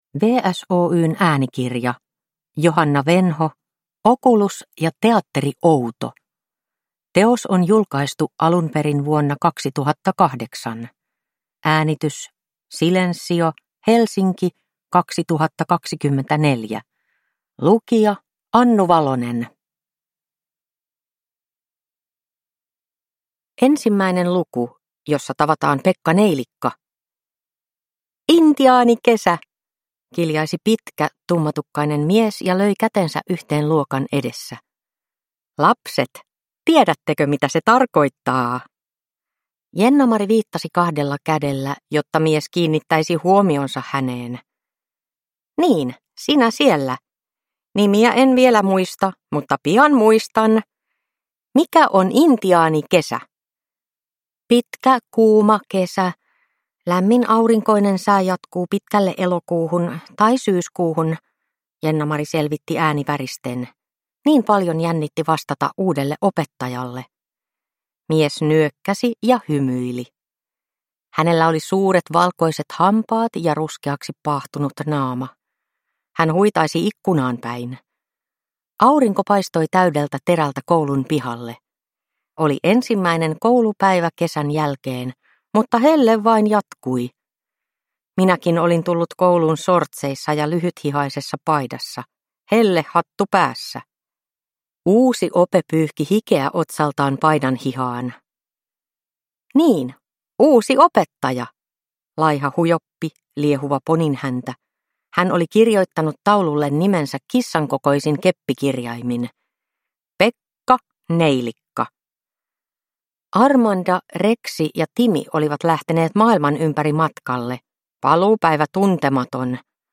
Okulus ja teatteri Outo – Ljudbok